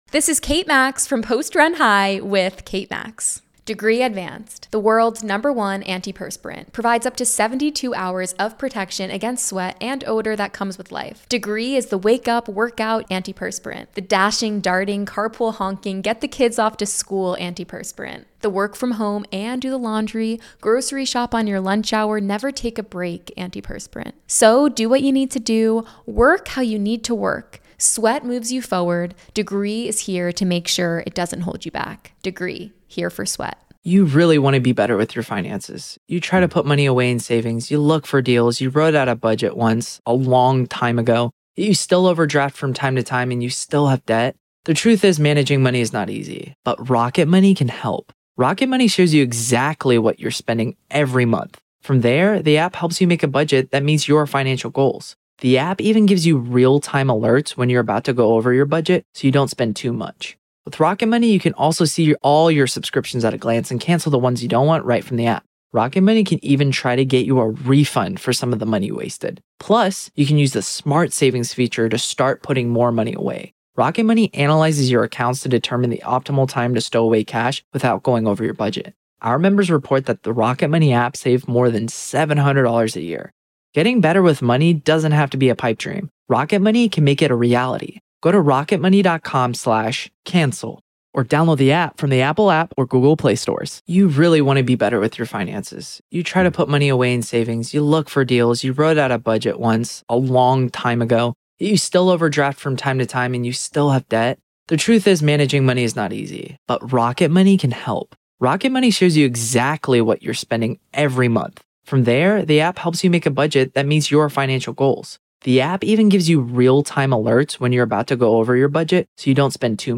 LIVE COURTROOM COVERAGE — NO COMMENTARY
There is no editorializing, no added narration, and no commentary — just the court, the attorneys, the witnesses, and the judge.